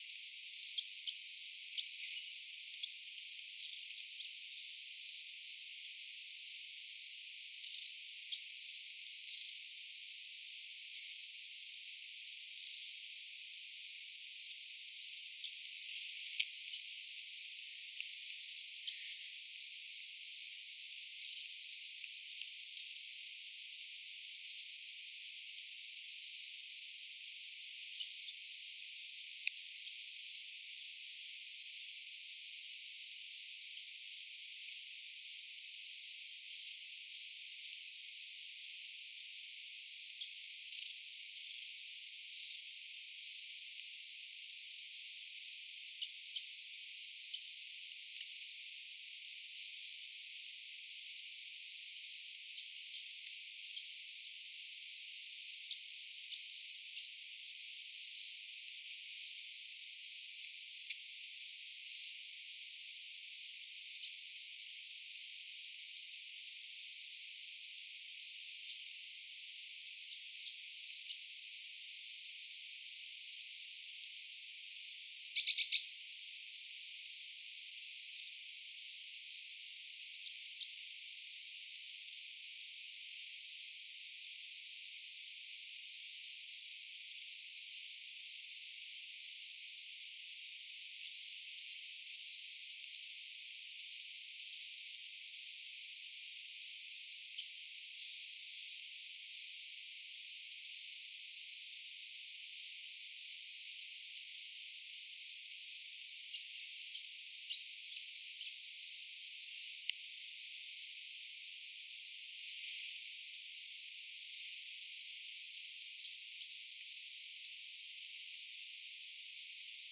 sinitiaislinnun vähän kuin käpylintumaista
hiljaista ääntelyä
sen ottaessa aurinkoa ja siistiityessä
sinitiaislintu_ottaa_aurinkoa_ja_siistii_hoyhenpukuaan_samalla_se_aantelee_hiukan_ehkapa_kapylintumaisesti.mp3